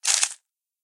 PixelPerfectionCE/assets/minecraft/sounds/mob/silverfish/step4.ogg at mc116
step4.ogg